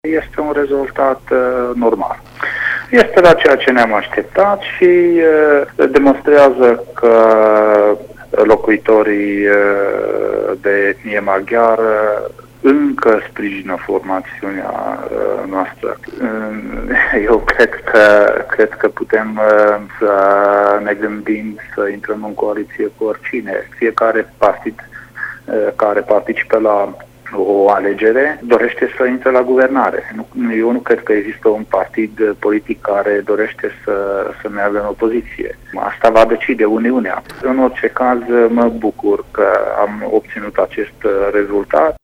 Și candidatul UDMR Harghita pentru Camera Deputaților, Bende Sandor, consideră că Uniunea a obținut rezultatul așteptat la alegerile parlamentare și că acest rezultat demonstrează că maghiarii încă sprijină formațiunea politică: